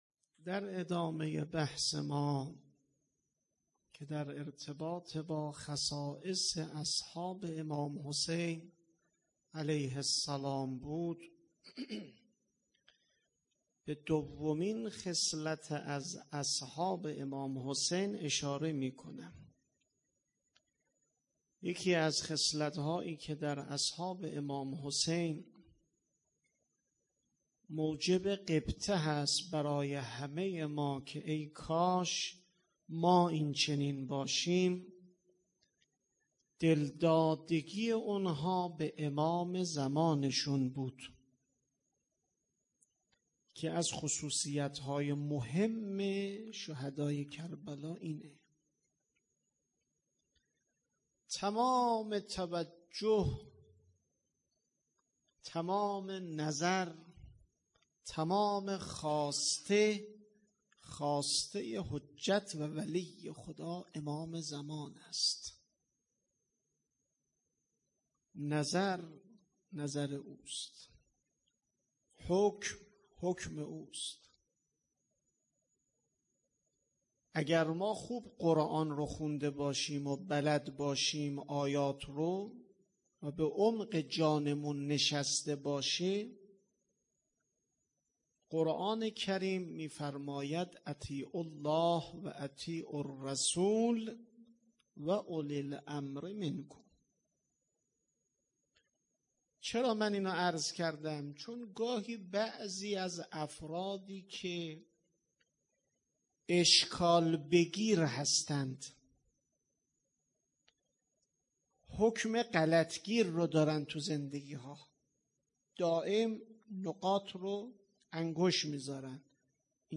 هیئت عقیله بنی هاشم سبزوار